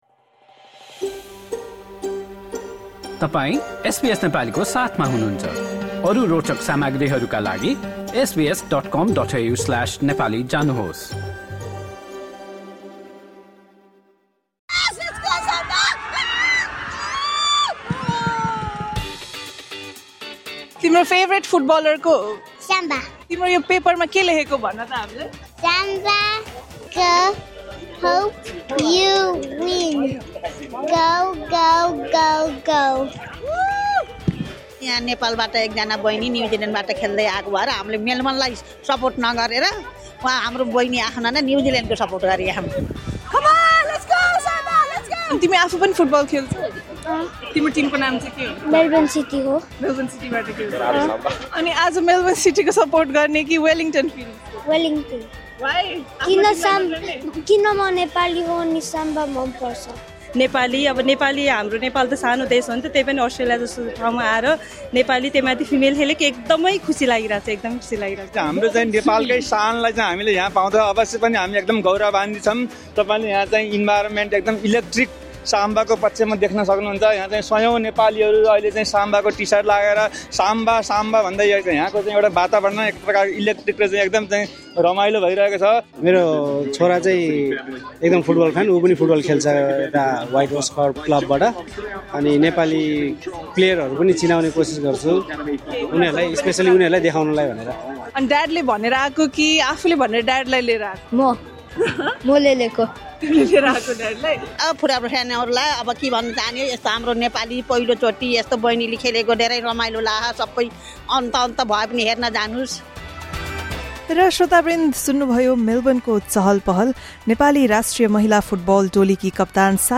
ए-लिग खेल्दाको अनुभव, अन्तर्राष्ट्रिय खेल जीवन र अस्ट्रेलियामा फुटबल प्रेमी तथा खेलप्रति इच्छुक दोस्रो पुस्ताका बालबालिकाकाका लागि रहेका अवसरहरू बारे भण्डारीसँग एसबीएस नेपालीले गरेको कुराकानी सुन्नुहोस्।